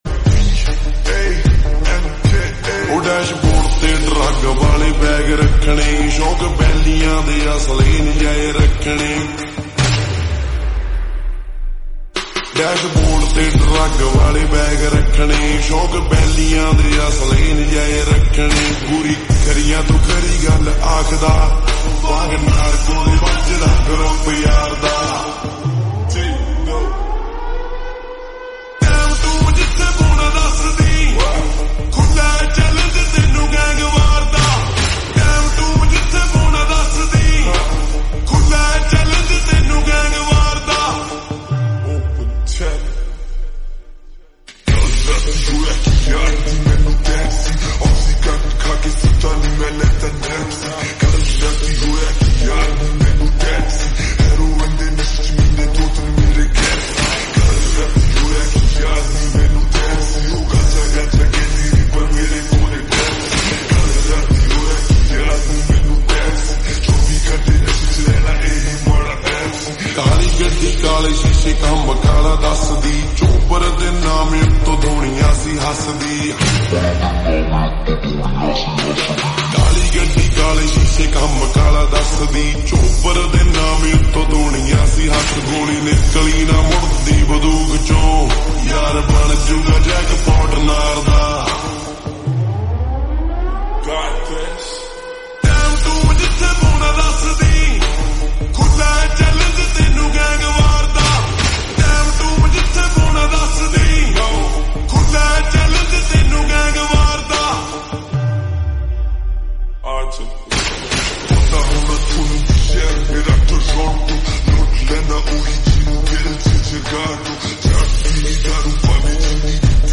BASE SLOWED REVERB